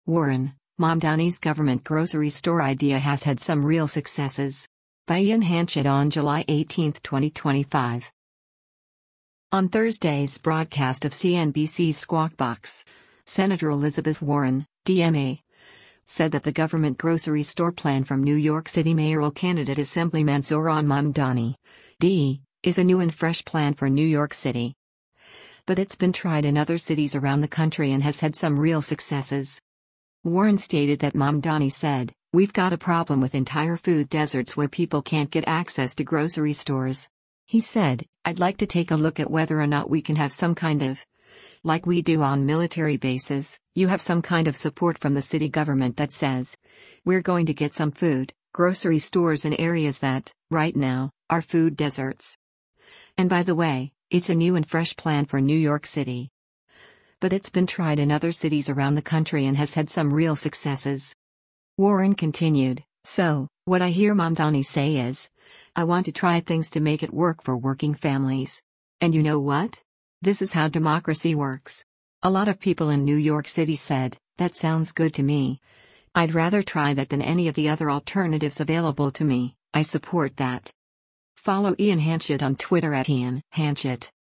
On Thursday’s broadcast of CNBC’s “Squawk Box,” Sen. Elizabeth Warren (D-MA) said that the government grocery store plan from New York City mayoral candidate Assemblyman Zohran Mamdani (D) is “a new and fresh plan for New York City. But it’s been tried in other cities around the country and has had some real successes.”